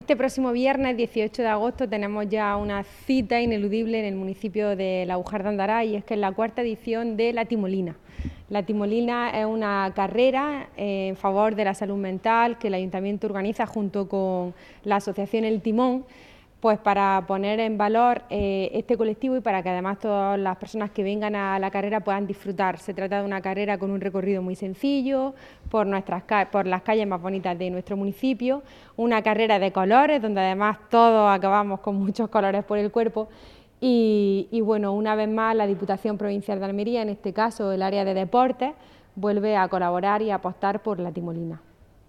14-08_laujar_timolina_alcaldesa_almudena_morales_out.mp3